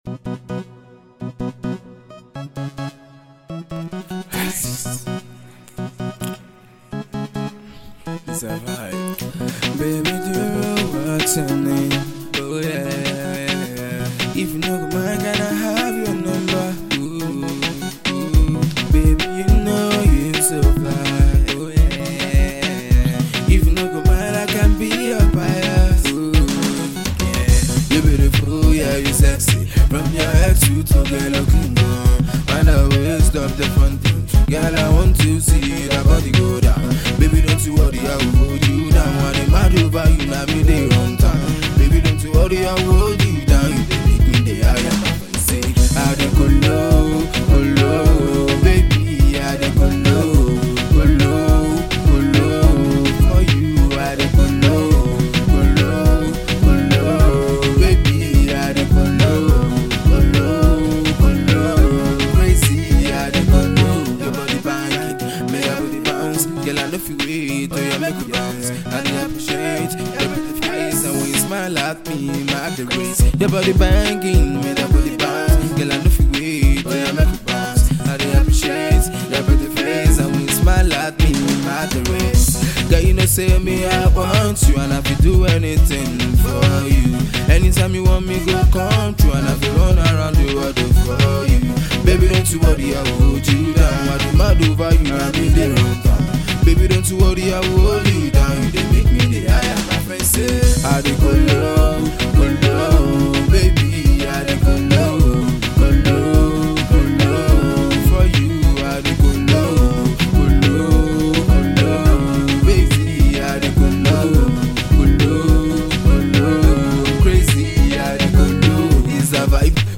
freestyle
groovy afro-highlife vibe